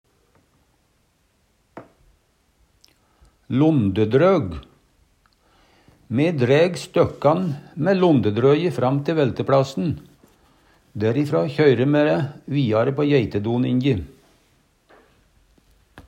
londedrøg - Numedalsmål (en-US)